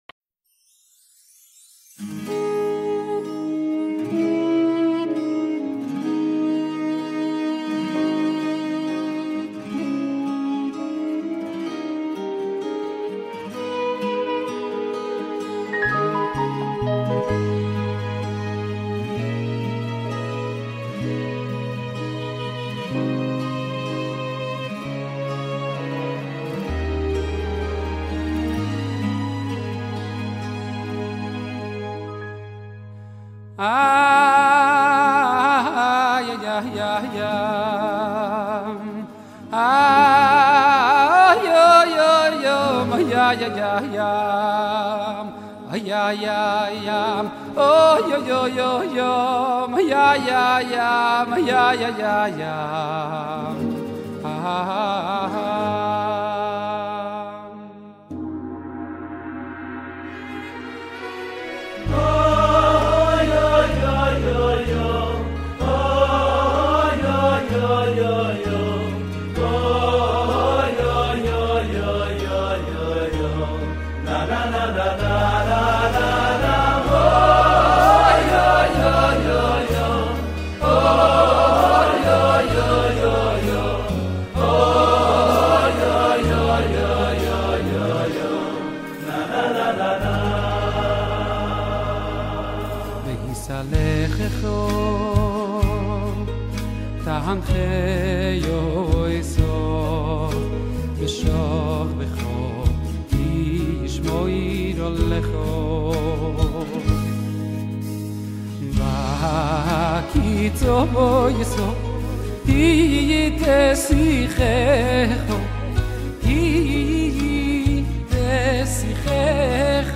בבקשה, למישהו יש את ההקלטה של הרב אלישיב לומד ומנגן???